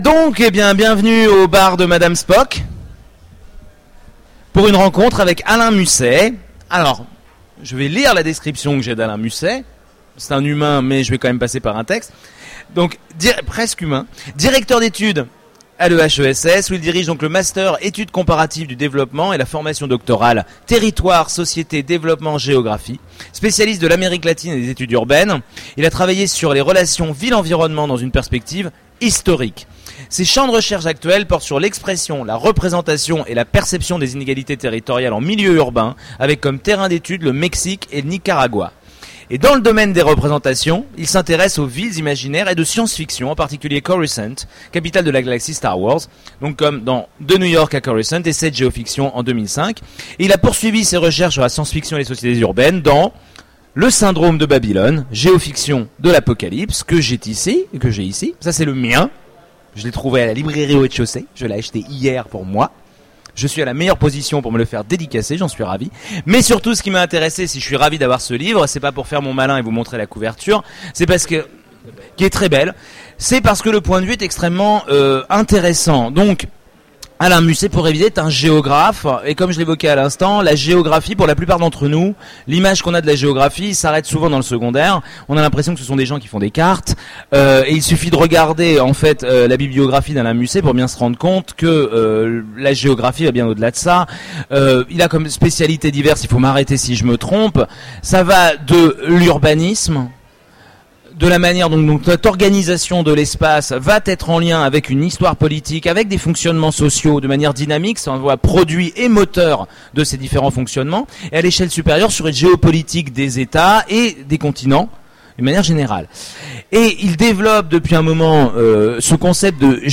Conférence
Mots-clés Rencontre avec un auteur Conférence Partager cet article